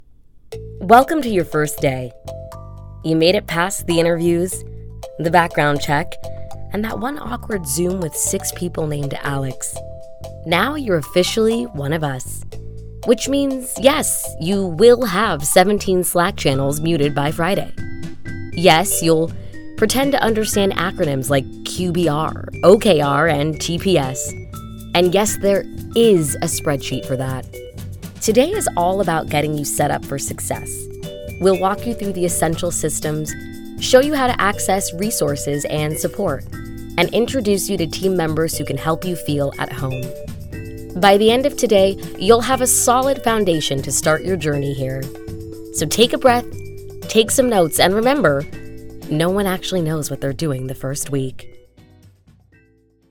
Warm, polished, and engaging voiceover talent with a background in theater & law
Corporate Training - Onboarding, Comedy, Humor, Tech, Millenial / Gen Z